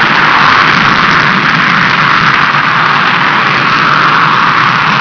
reheat.wav